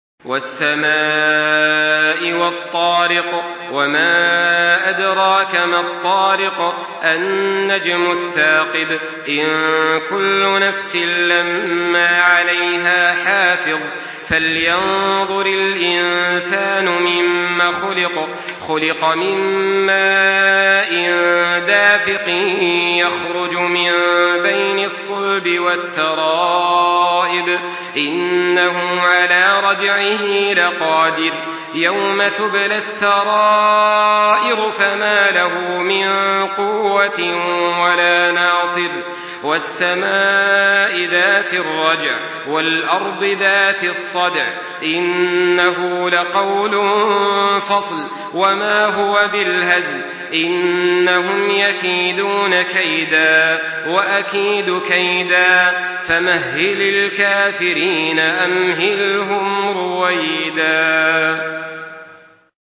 Surah আত-ত্বারেক MP3 by Makkah Taraweeh 1424 in Hafs An Asim narration.
Murattal Hafs An Asim